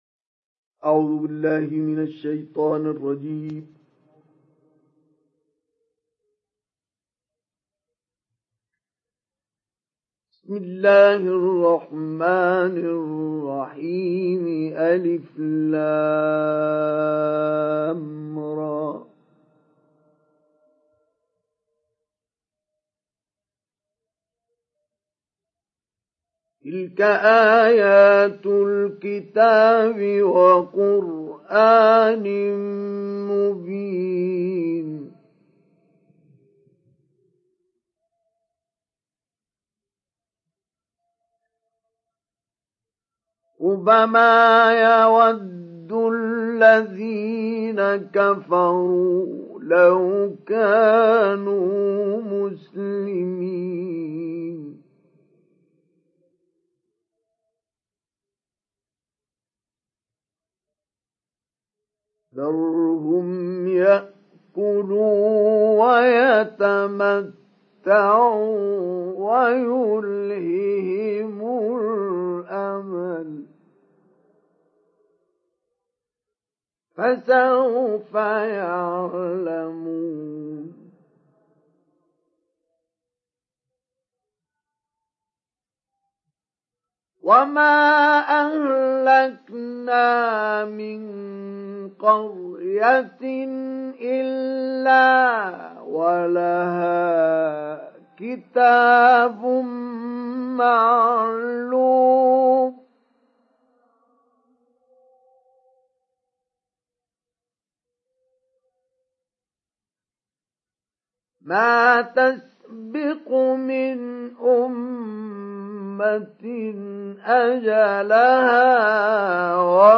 Download Surat Al Hijr Mustafa Ismail Mujawwad